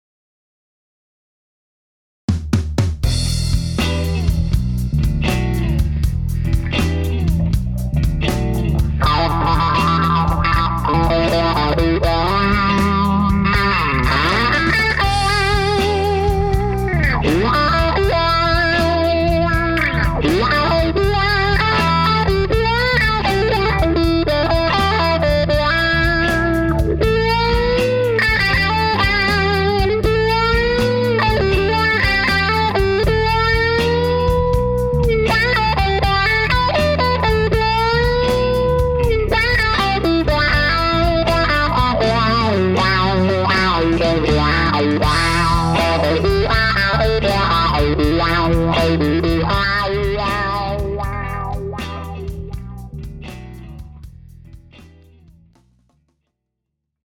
The sweep is perfect on this pedal, and unlike other wah pedals I’ve tested, doesn’t have a “breaking point” where the wah effect comes on suddenly. It’s nice and gradual.
🙂 Once I got a chance, I got a backing track going from one of my songs called, “In the Vibe,” and recorded a quick clip. I realize that I could probably have been a bit more dramatic with the wah effect in certain places, but I’m still getting used to the sweep.